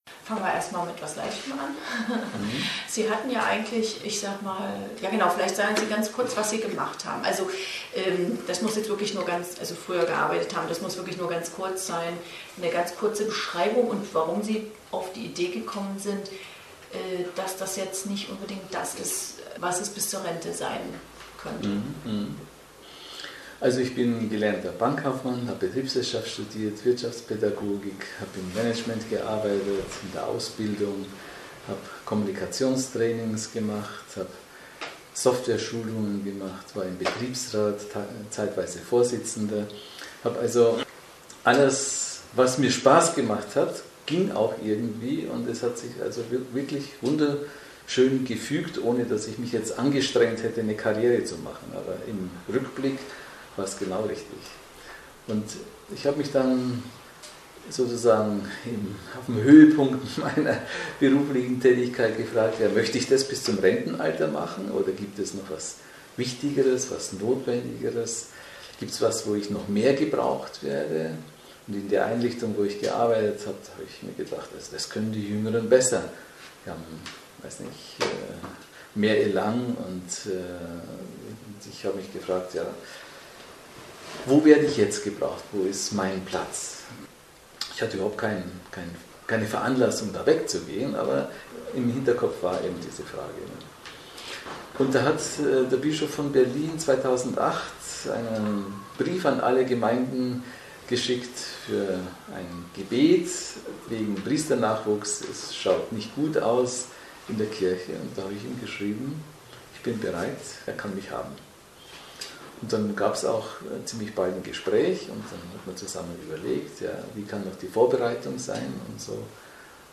• Interview zu meiner Tätigkeit als Seelsorger im Nordmagazin des NDR